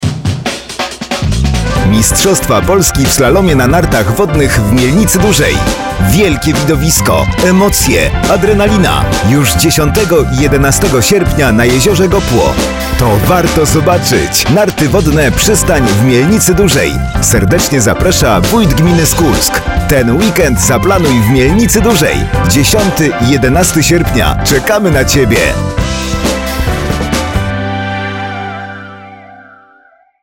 Spot Radio Merkury[944.49 KB]